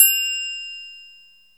• Ride Sound Clip F Key 03.wav
Royality free drum ride tuned to the F note.
ride-sound-clip-f-key-03-31e.wav